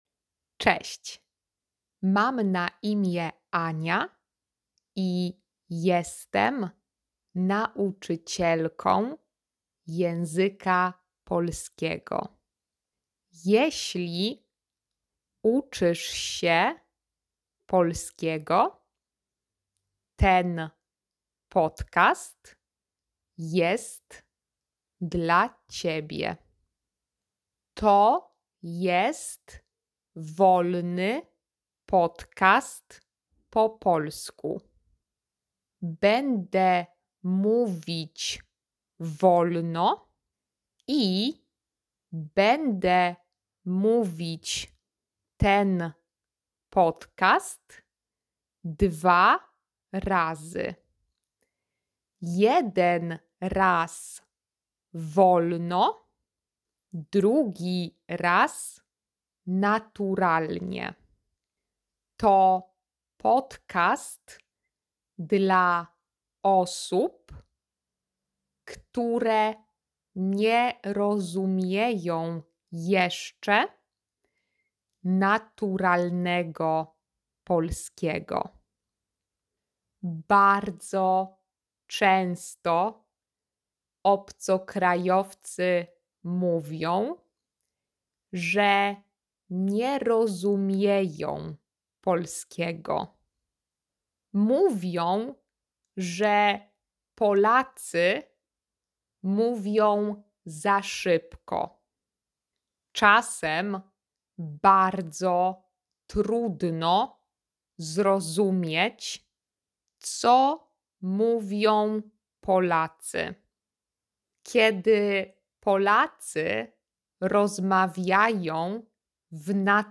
Podcast-in-slow-polish.mp3